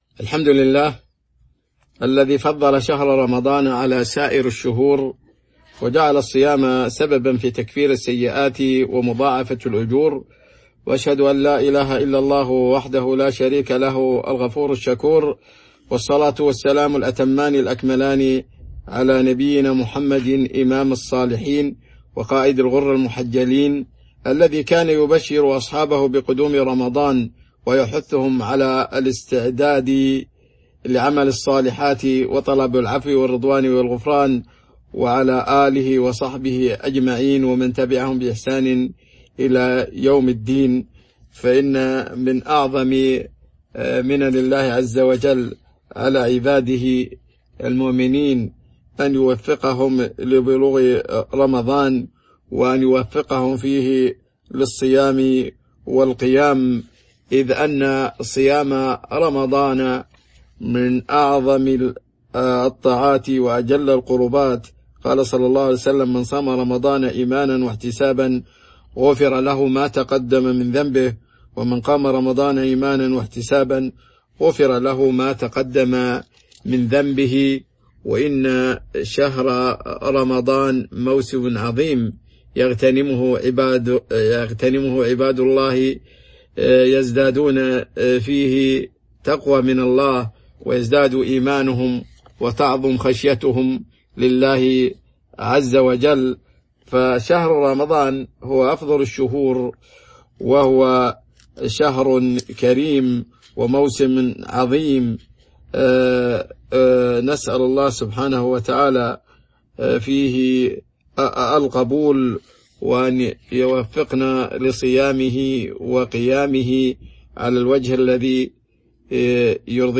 تاريخ النشر ١٤ رمضان ١٤٤٢ هـ المكان: المسجد النبوي الشيخ